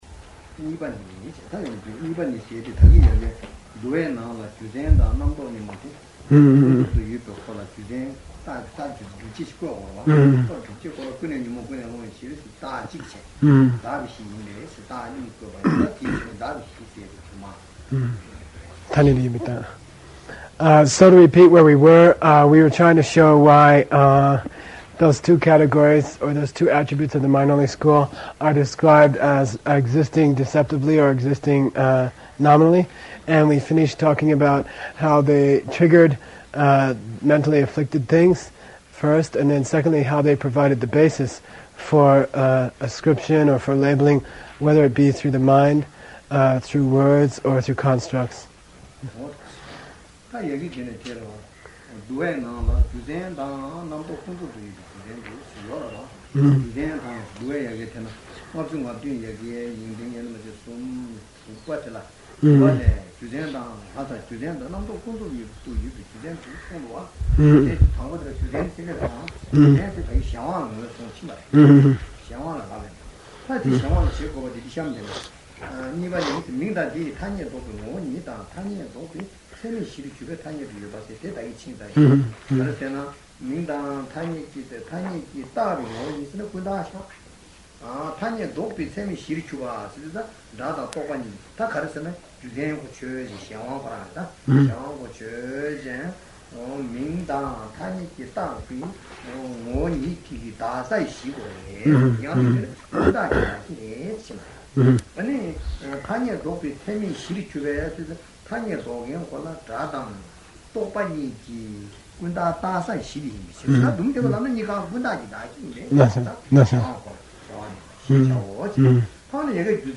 Bylakuppe